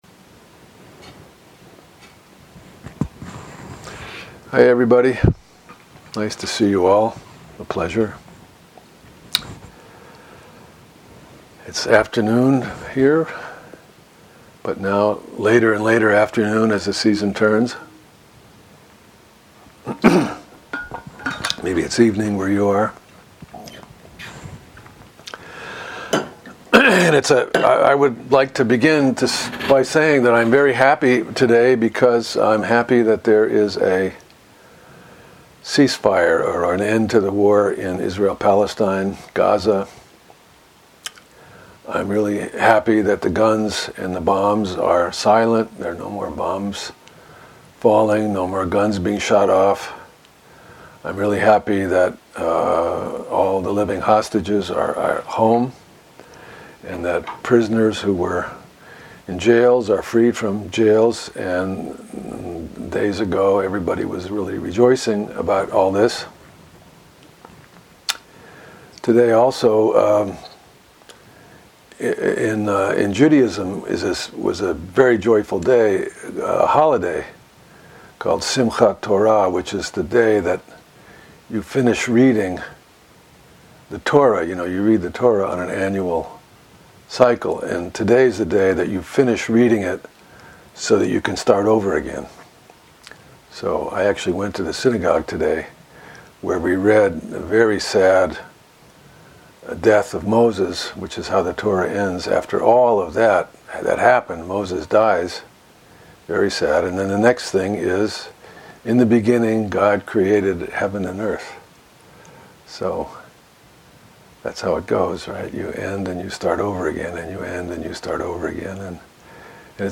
the fourteenth talk of the Gandavyuha Sutra (Avatamsaka Sutra Chapter 39) at the Everyday Zen Dharma Seminar. The Gandavyuha Sutra is the story of the spiritual pilgrimage of the monk Sudhana, who visits and learns from many spiritual masters. This is the final chapter 39 of the much larger Avatamsaka Sutra.